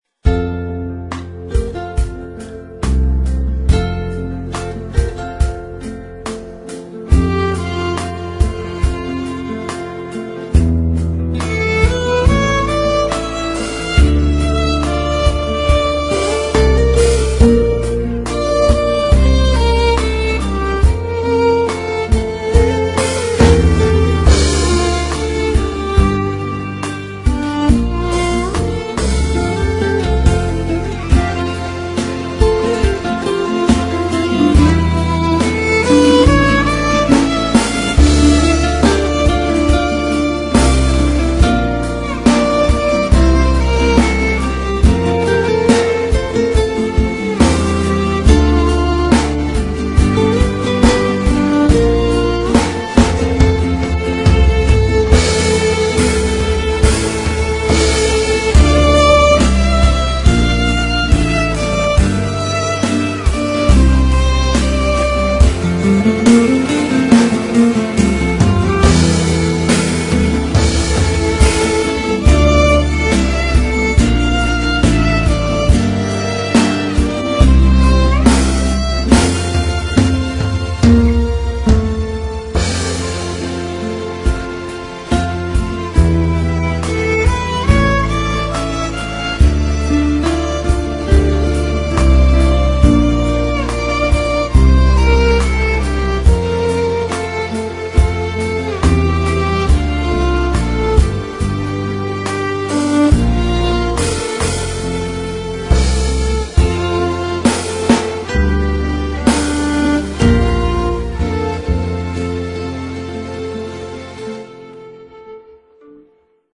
Violin E Guitar